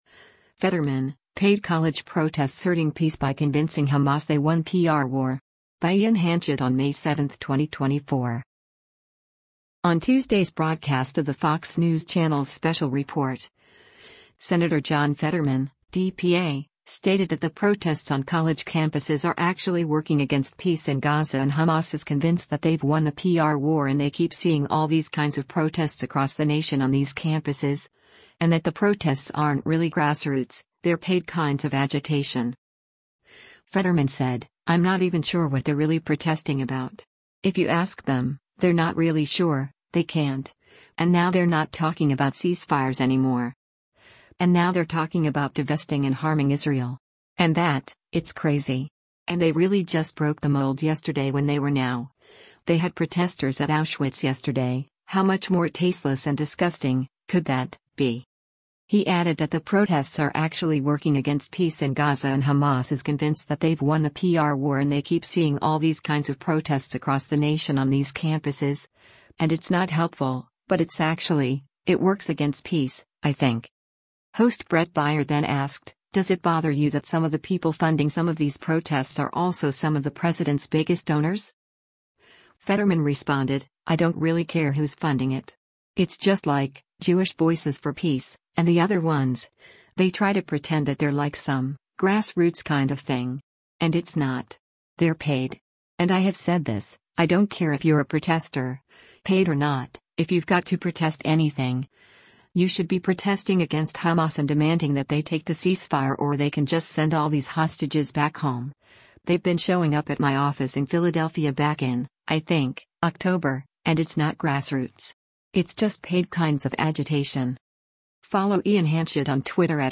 On Tuesday’s broadcast of the Fox News Channel’s “Special Report,” Sen. John Fetterman (D-PA) stated that the protests on college campuses are “actually working against peace in Gaza and Hamas is convinced that they’ve won the PR war and they keep seeing all these kinds of protests across the nation on these campuses,” and that the protests aren’t really grassroots, they’re “paid kinds of agitation.”
Host Bret Baier then asked, “Does it bother you that some of the people funding some of these protests are also some of the president’s biggest donors?”